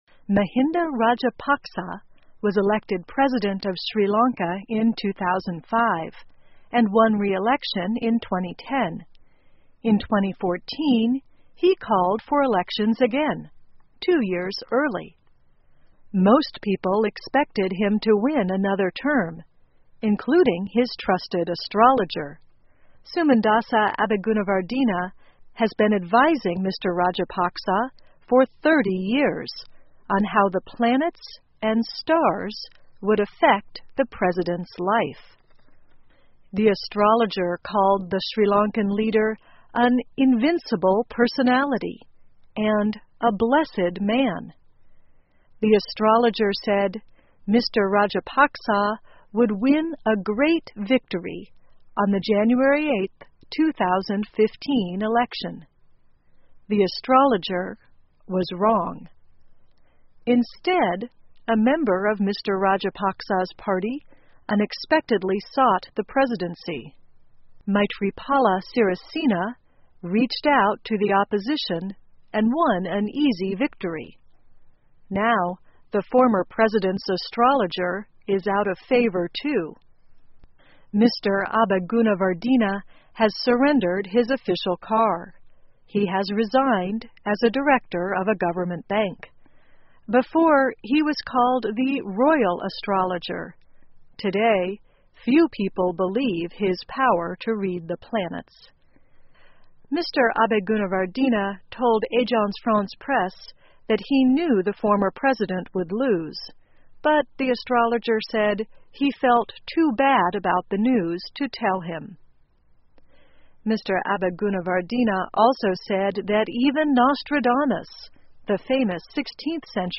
VOA慢速英语2015 斯里兰卡选举考验占星师水平 听力文件下载—在线英语听力室